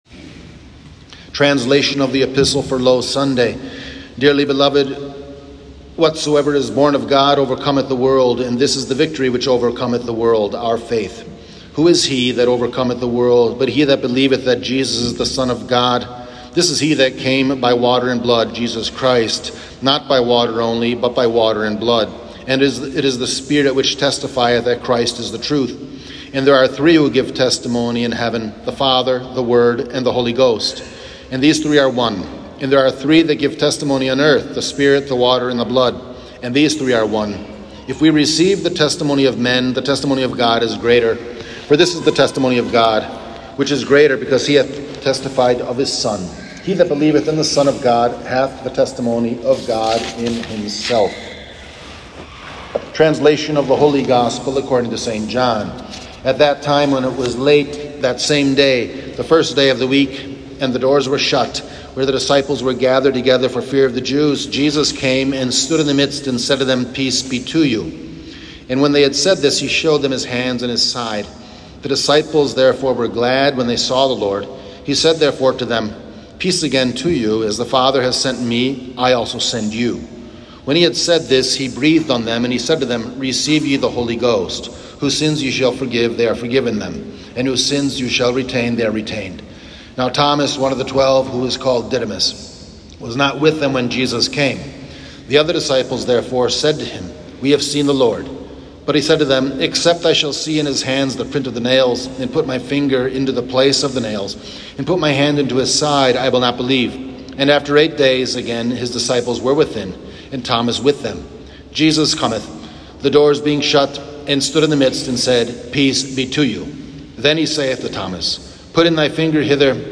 Low Sunday / Divine Mercy Sunday — Homily